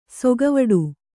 ♪ sogavaḍu